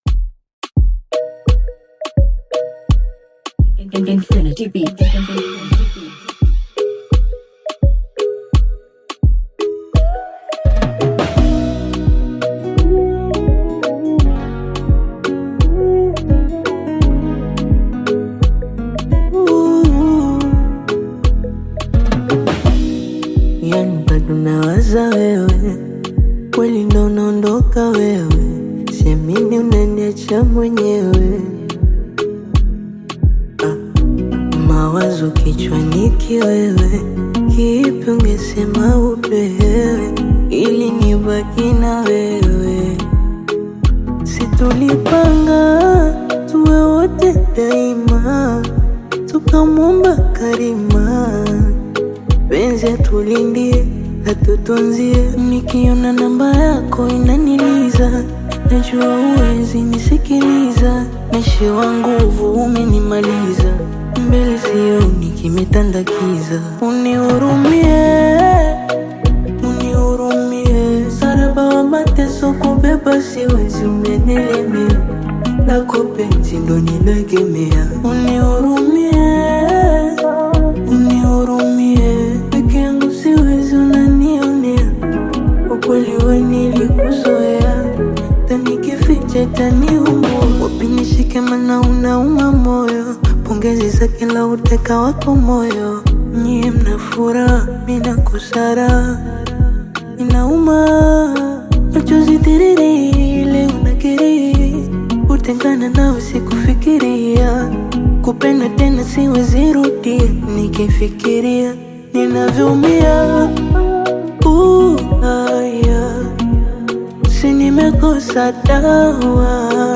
Bongo Flava
is a heartfelt love song